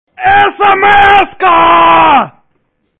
/24kbps) 16kbps (6кб) Описание: мужик орет не своим голосом о том,что пришло смс сообщение.